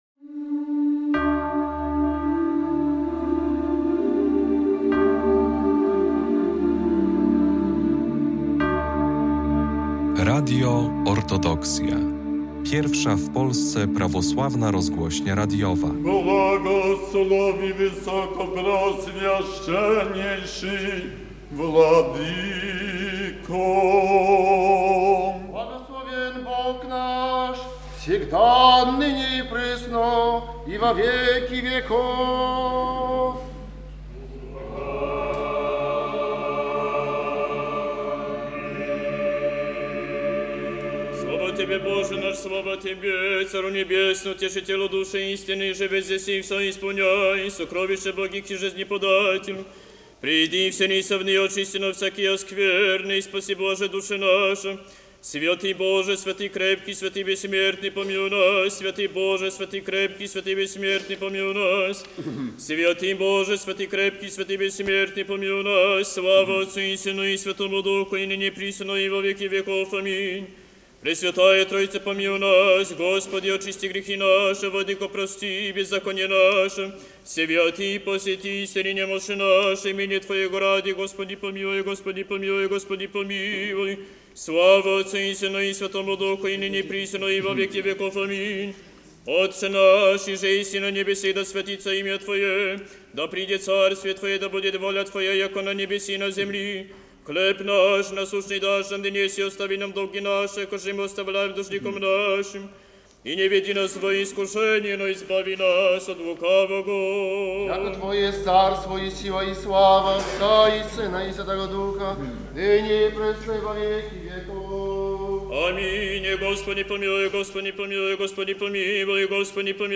Nagranie wieczerni z obrzędem przebaczenia win
2 marca w ostatnią niedzielę przed wielkim postem w katedrze św. Mikołaja Cudotwórcy w Białymstoku była sprawowana wielka wieczernia z obrzędem przebaczenia win. Zapraszamy do wysłuchania retransmisji tego nabożeństwa.